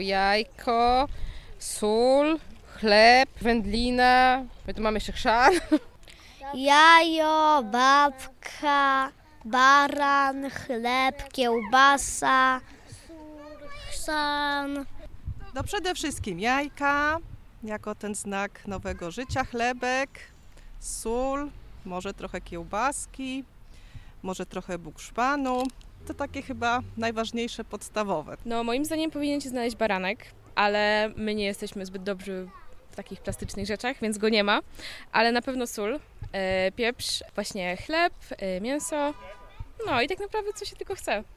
Pod kościołem spotkaliśmy rodziny z dziećmi, które zmierzały na poświęcenie pokarmów.